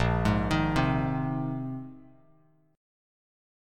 Bb9sus4 chord